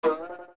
• Ironically, they are technically the same audio file, but the Slingshot version is slightly sped-up from the Bow's.
OOT_Slingshot_Twang.wav